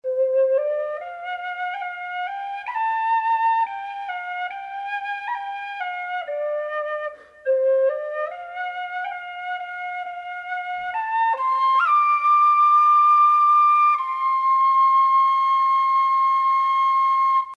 Sie haben einen klaren, mittellauten Sound.
Soundbeispiel Generation in C